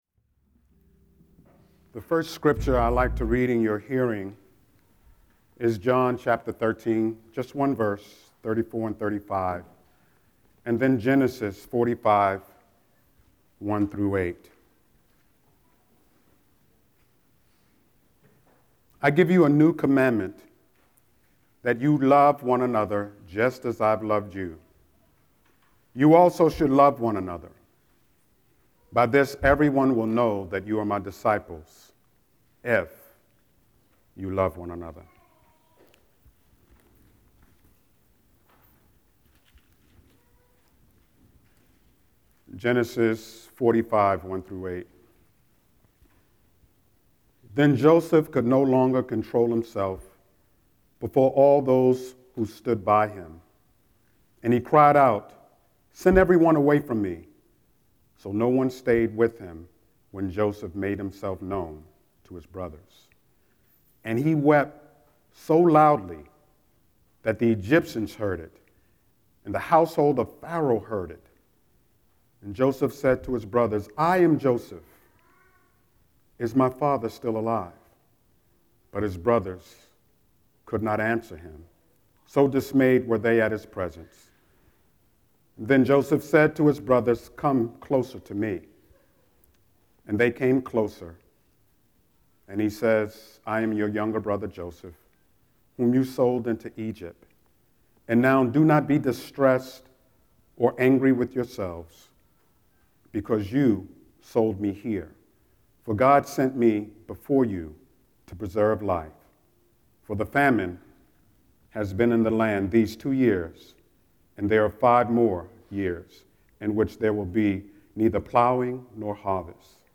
08-16-Scripture-and-Sermon.mp3